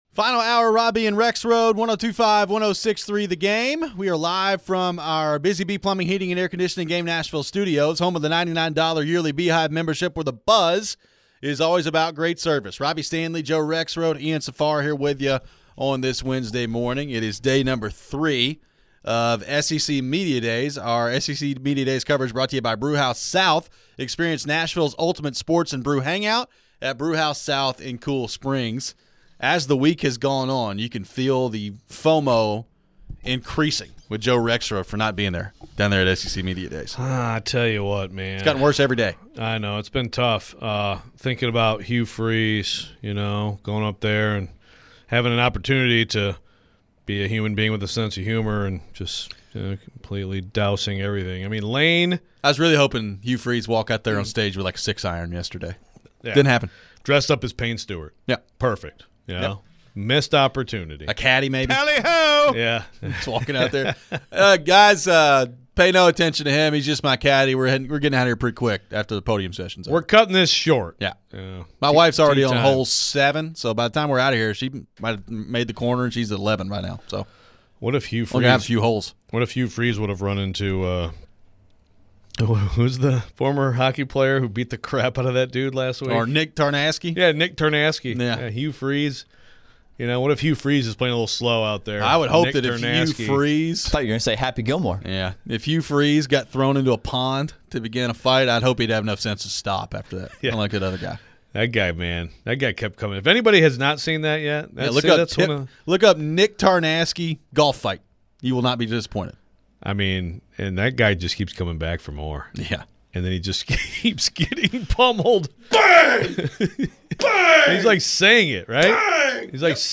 How possible is it the team has the QB situation more under control than we think? We head to the phones.